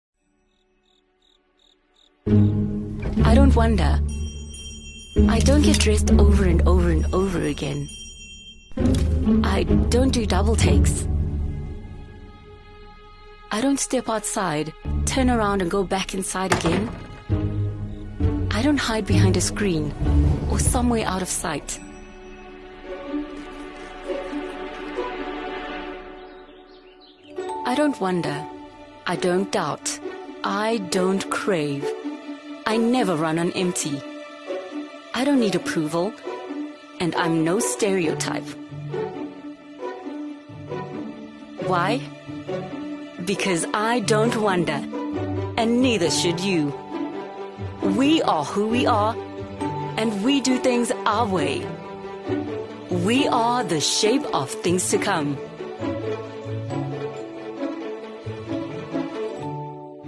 animation, articulate, bright, commercial, confident, conversational, friendly, High Energy, Trendy